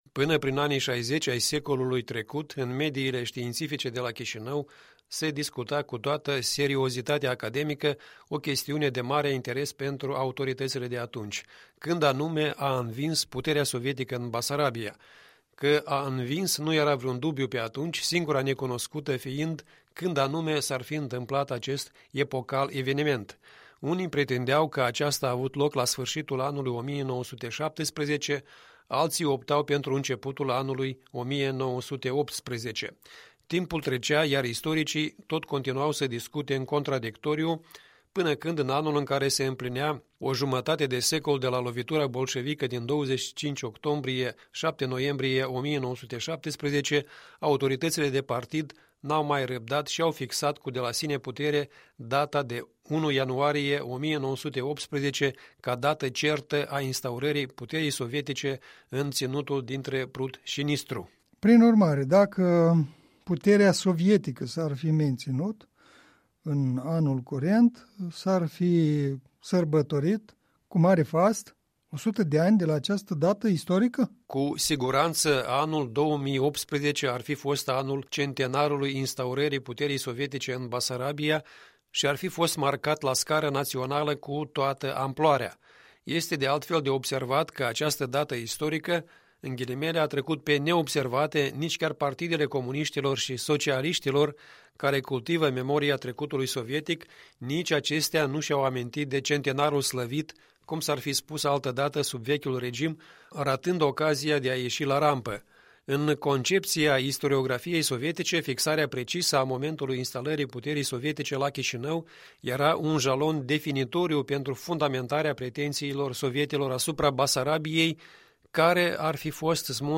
Un punct de vedere săptămânal în dialog.